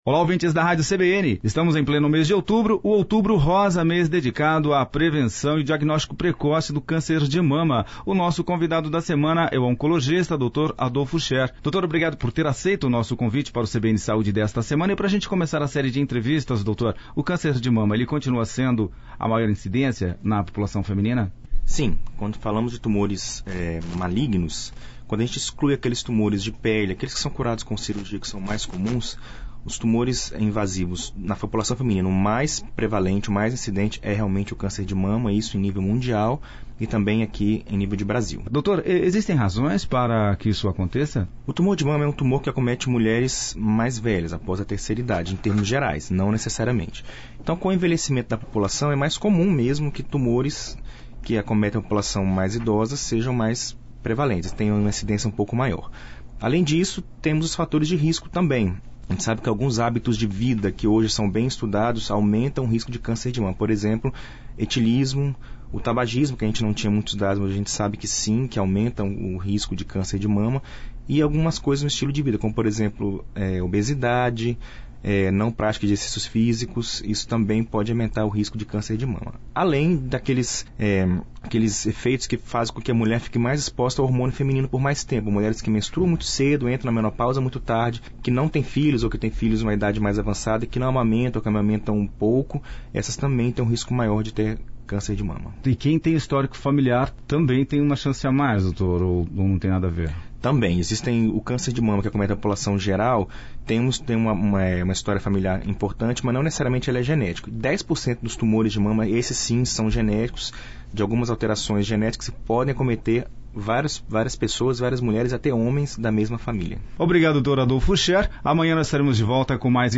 A entrevista foi ao ar em 21/10/2019